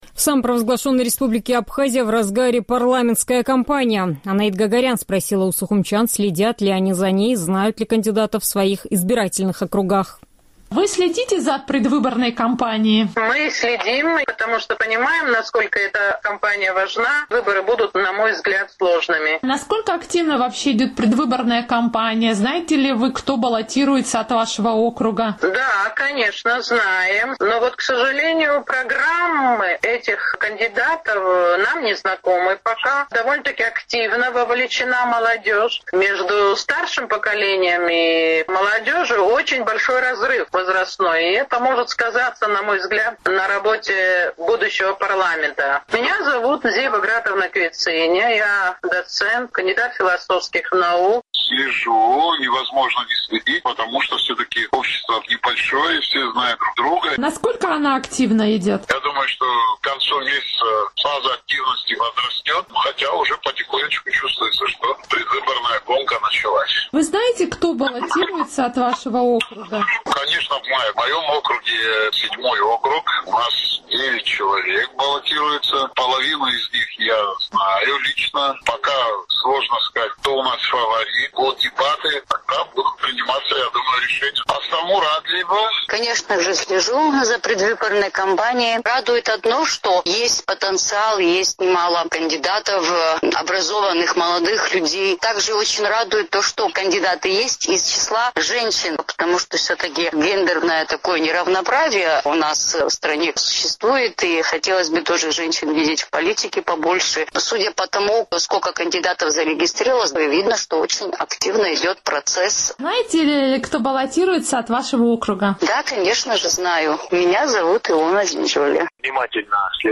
В Абхазии в разгаре парламентская кампания. «Эхо Кавказа» спросило у жителей Сухума, следят ли они за ней и знают ли кандидатов в своих избирательных округах?